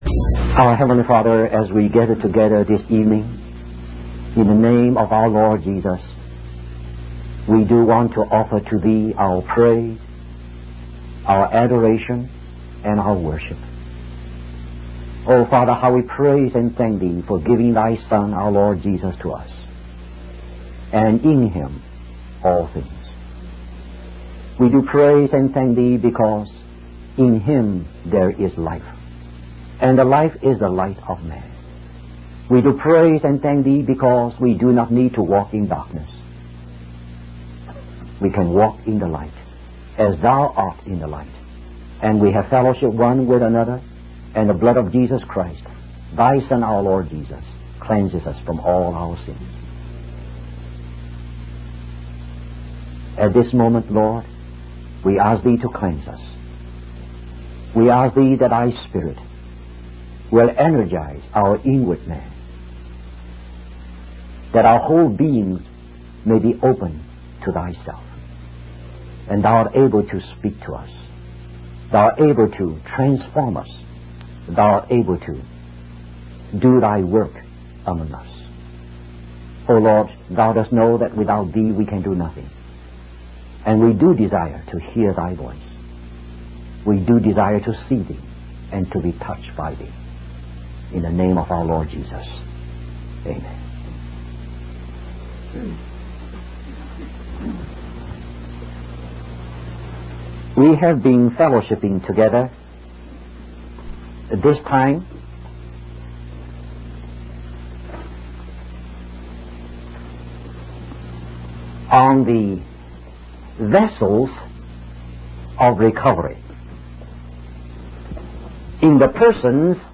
In this sermon, the speaker emphasizes the importance of divine revelation, authority, and wisdom in God's work of recovery. He uses the analogy of blueprints to explain that while the pattern of building God's house (the church) is revealed in the Bible, it takes wisdom to interpret and apply it. The speaker also highlights the need for love in gathering materials for the building of God's house and in winning souls for Christ.